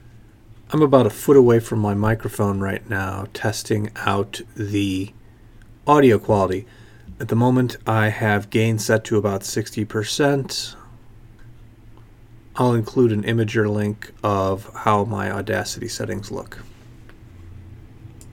Getting metallic sound on Blue Yeti
I've been using a Blue Yeti mic with Audacity every few months, and for the first time I'm getting some metallic-sounding voiceover.
I have gain set to about 60% on the mic, Project rate is 48kHz.
Unedited clip attached. visual on audacity Imgur: The most awesome images on the Internet Attachments test link home recording.mp3 test link home recording.mp3 232.8 KB · Views: 132